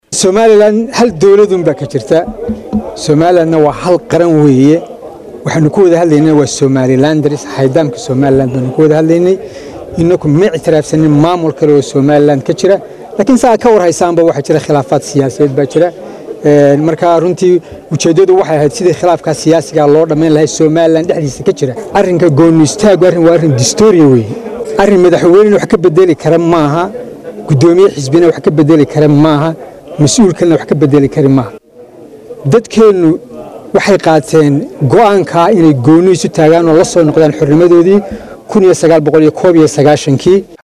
December 28 2016 (Puntlandes)-Wasiirka arimaha dibada maamulka Somaliland Sacad Cali Shire  oo la hadlay Warbaahinta ayaa sheegay in Mowqifka maamulka Somaliland ee ah gooni isku taaga in aysan dhici doonin in ay marnaba  ka tanaasulaan.